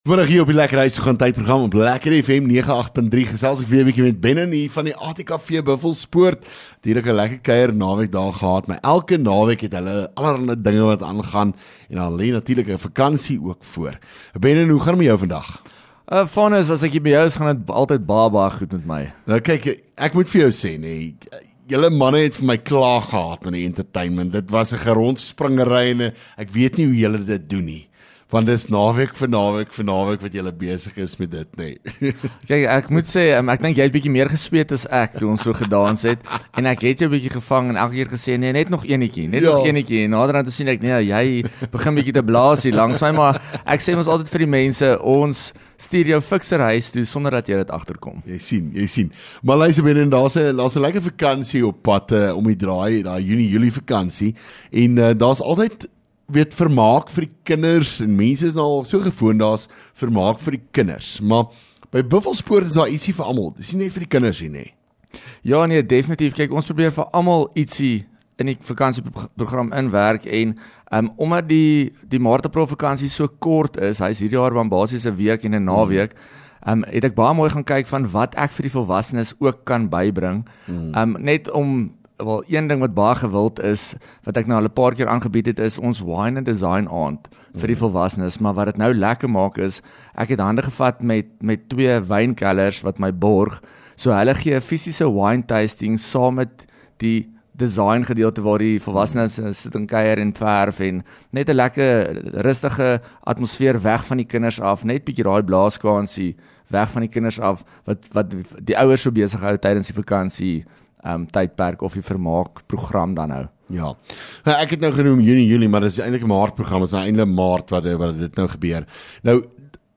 LEKKER FM | Onderhoude 11 Mar ATKV-Buffelspoort